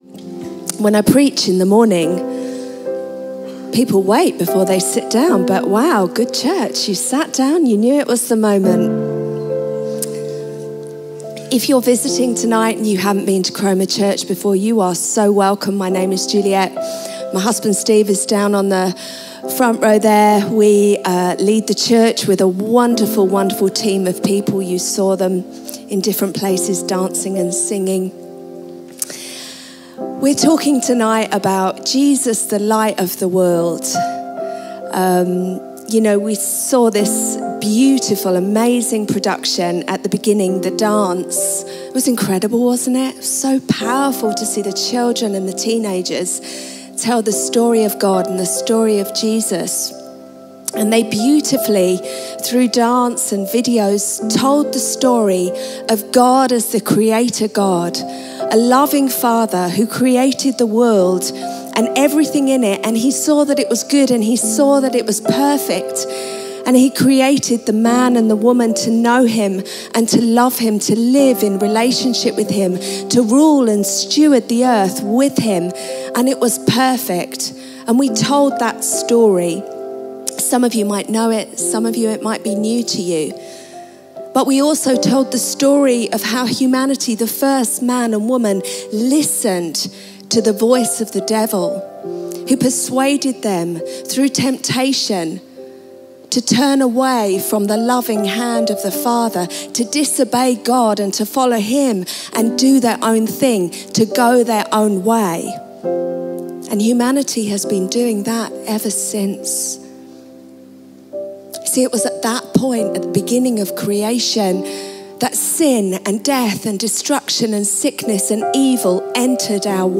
Chroma Church - Sunday Sermon Jesus Christ – Light Of The World Dec 19 2022 | 00:36:47 1x 00:00 / 00:36:47 Subscribe Share RSS Feed Share Link Embed